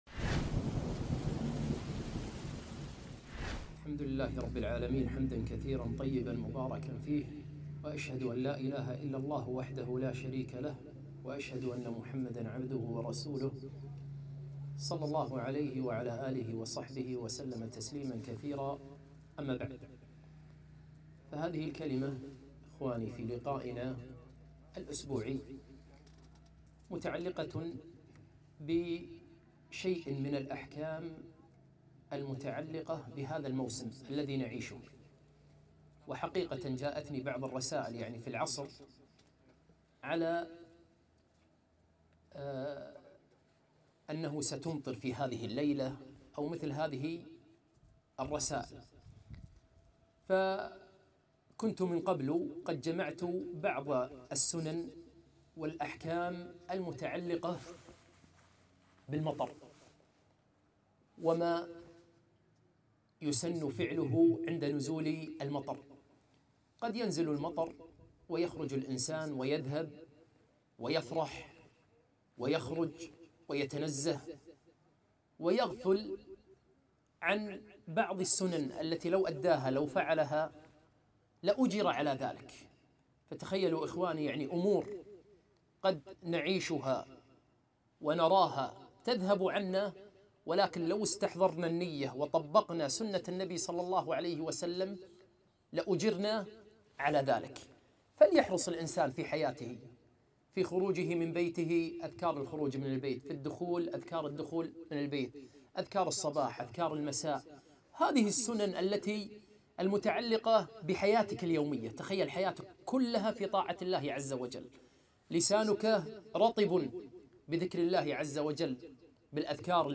كلمة - من الأحكام المتعلقة